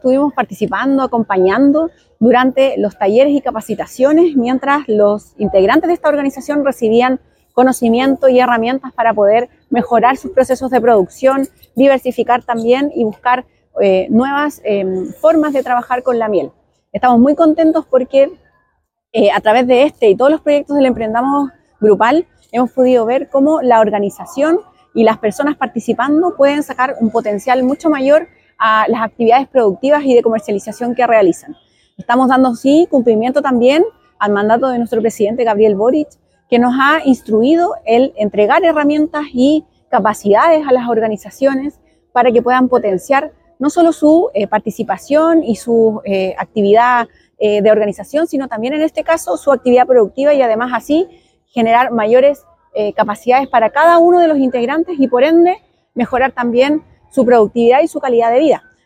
Directora regional del FOSIS